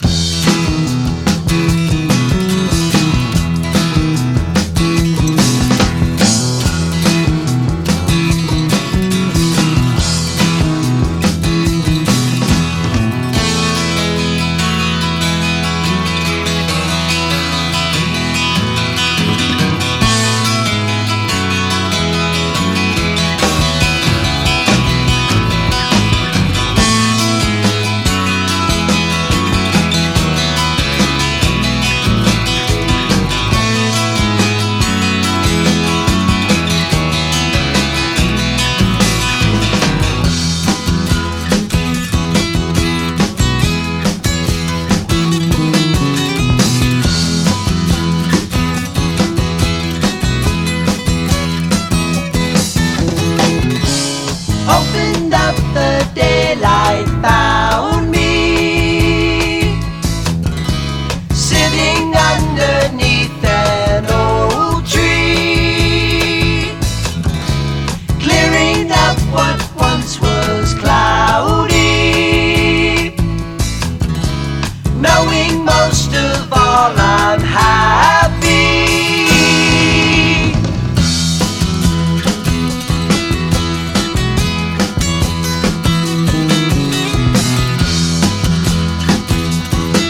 ドラムブレイク入りのレアグルーヴあり、ソフト・ロックあり、フォーキー・ラテン・ソウルあり、のありえないクオリティー！